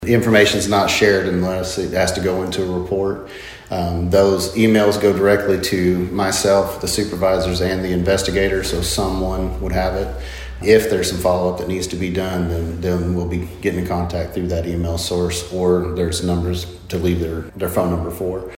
Jimmy Gray on how info shared 7-16.mp3